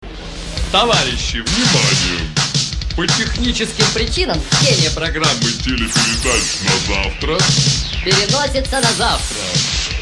Подскажите, кто знает, каким плагином сделать такой эффект (питч - понижение и повышение голоса) + аудиопример Вложения pitch-audio.mp3 pitch-audio.mp3 237,2 KB · Просмотры: 596